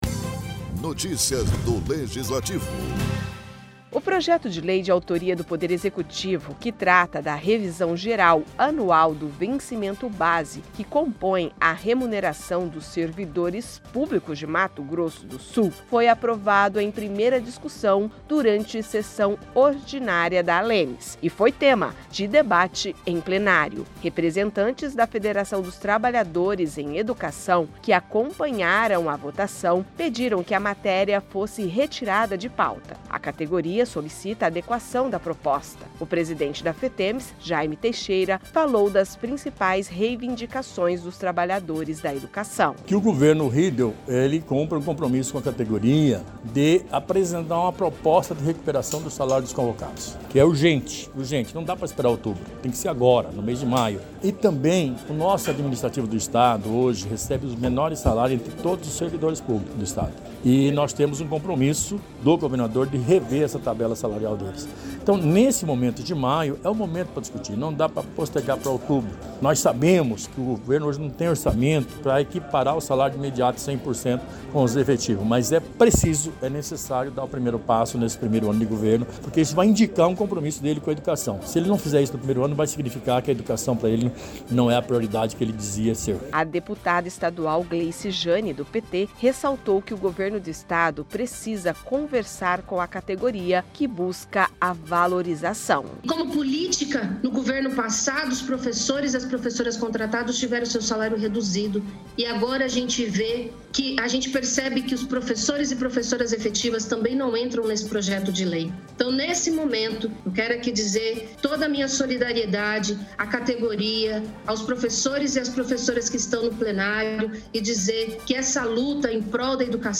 O Projeto de Lei de autoria do Poder Executivo, que trata da revisão geral anual do vencimento-base que compõem a remuneração dos servidores públicos de Mato Grosso do Sul, que foi aprovado em primeira discussão, durante sessão ordinária da ALEMS, foi tema de debate em plenário.